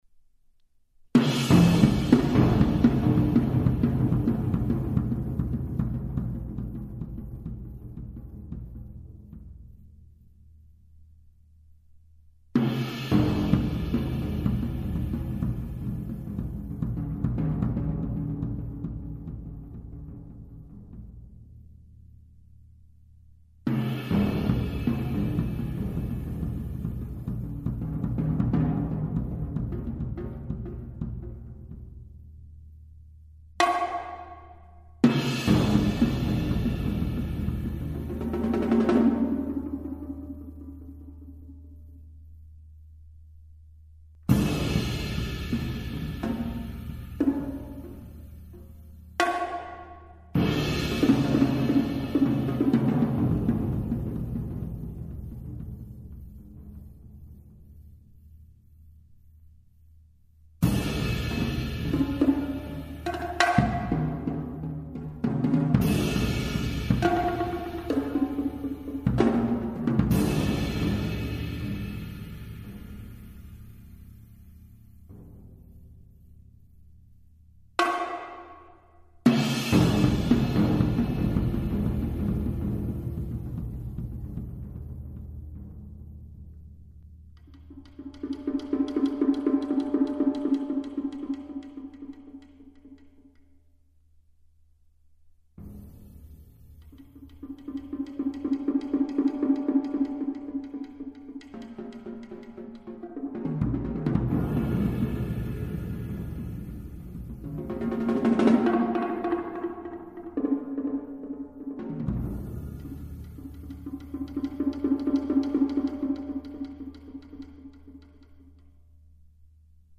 Genre: Solo Multiple Percussion
# of Players: 1
Kick Drum
Small Concert Bass Drum (tuned high)
2 Concert Toms (low & medium)
Snare Drum
Bongos (tuned high)
Brake Drum (pitched "D" if possible)
Sizzle Cymbal (20" or larger)